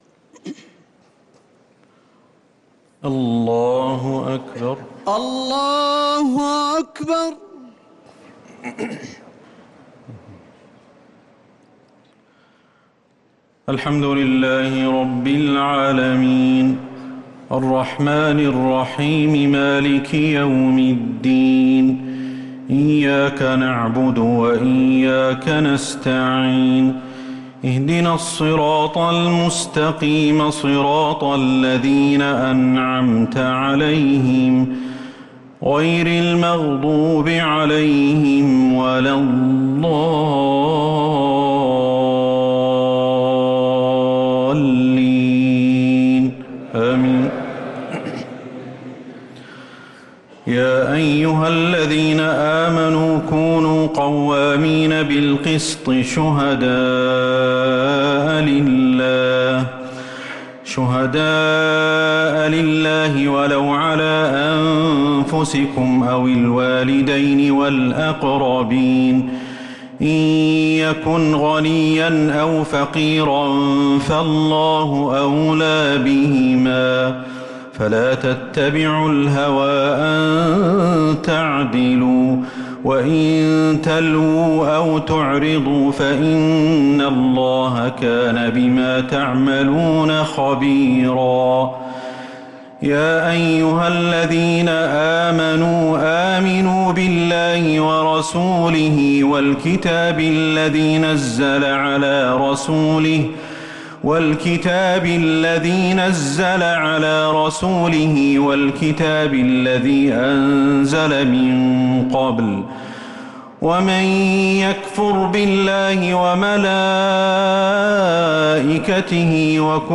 صلاة التراويح ليلة 7 رمضان 1445 للقارئ علي الحذيفي - التسليمتان الأخيرتان صلاة التراويح